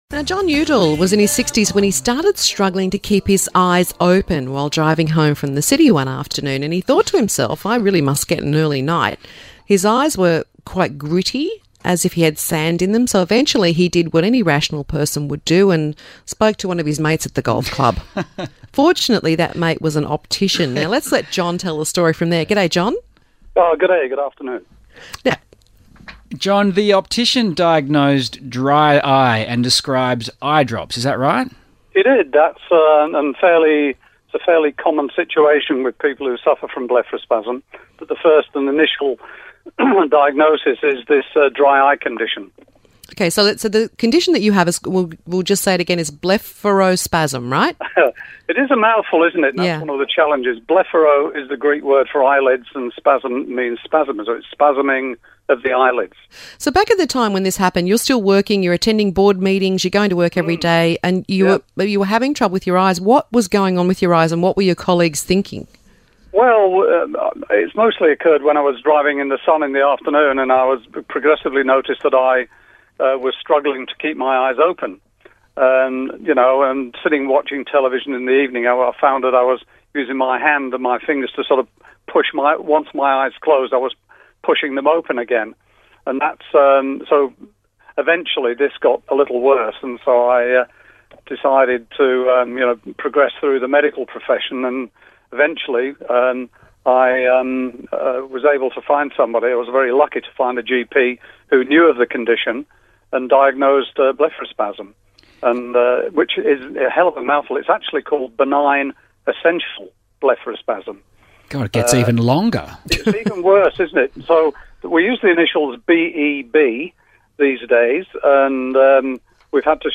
discussing Blepharospasm on 6PR Millsy at Midday